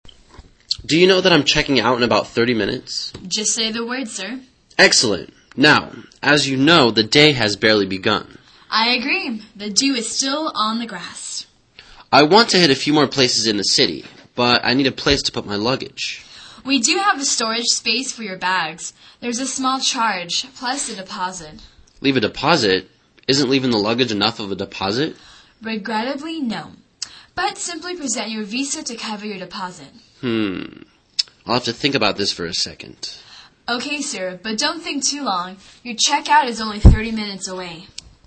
旅馆英语对话-Storing Luggage(3) 听力文件下载—在线英语听力室